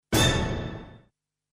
Ambient sound effects
Descargar EFECTO DE SONIDO DE AMBIENTE AVISO - Tono móvil